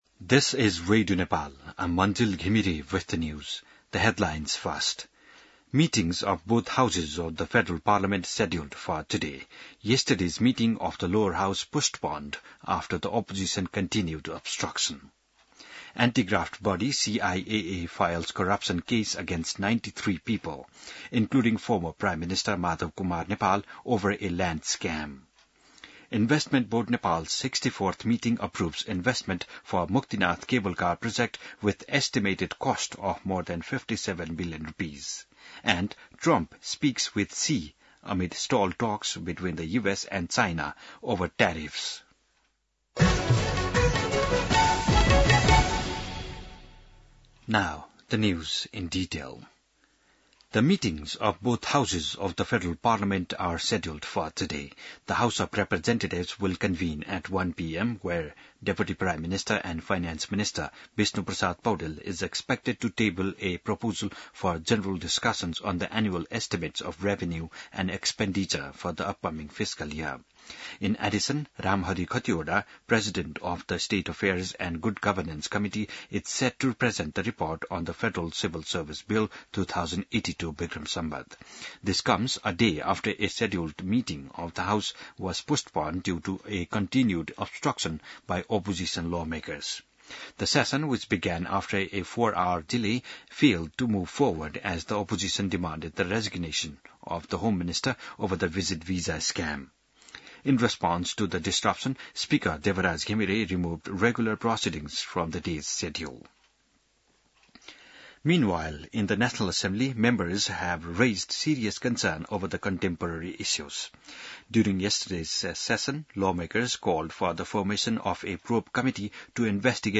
An online outlet of Nepal's national radio broadcaster
बिहान ८ बजेको अङ्ग्रेजी समाचार : २३ जेठ , २०८२